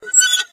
gadget_rotate_01.ogg